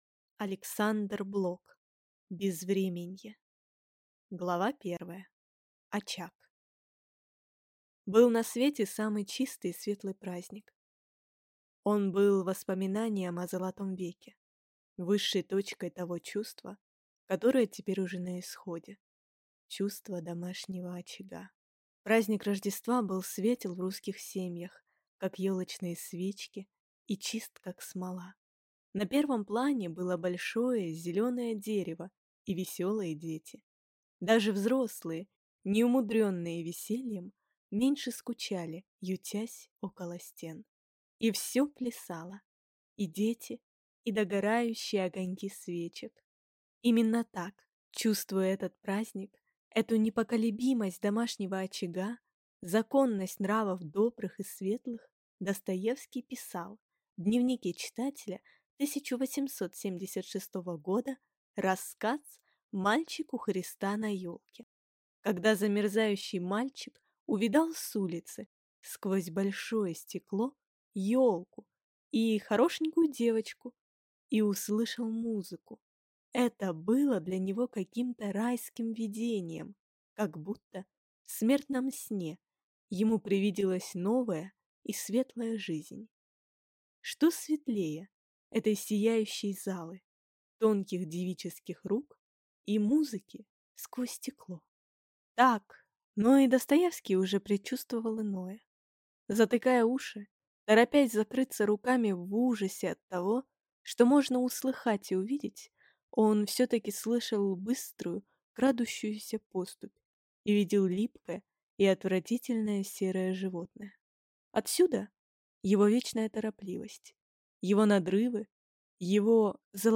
Аудиокнига Безвременье | Библиотека аудиокниг